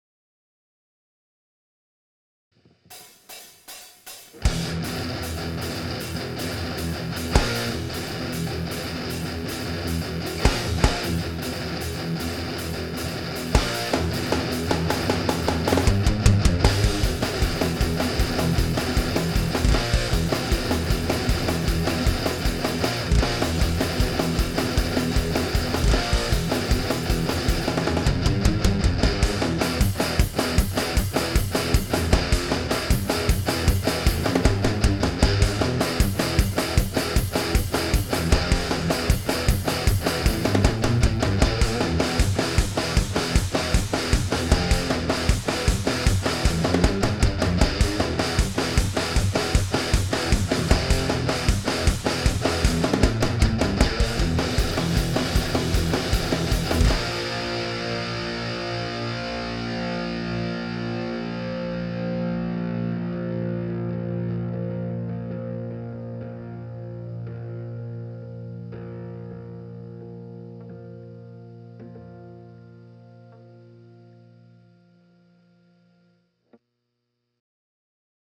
I'm thinkin' a bit of notching in that whistly high-end, a little flub-cutting and a decent bass tone would make it acceptable.
Shockingly full for an 8" speaker.
That sounds better under a microphone than it has any right to.